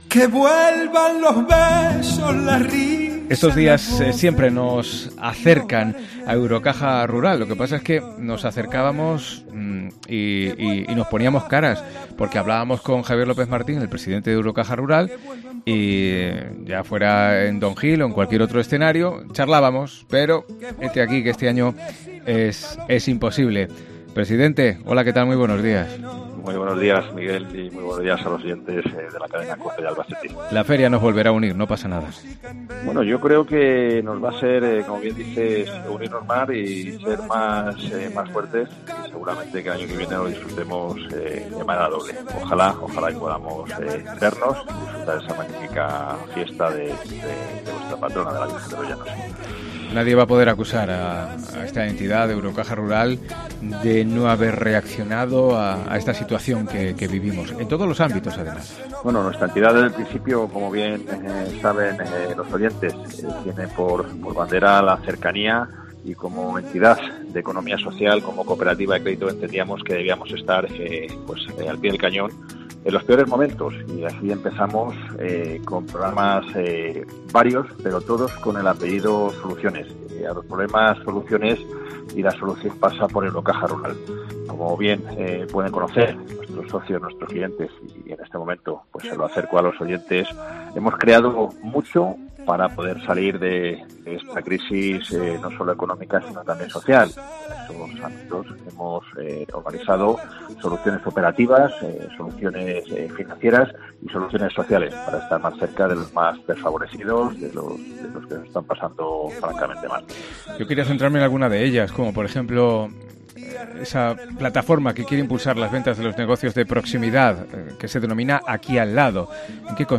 ENTREVISTA COPE ALBACETE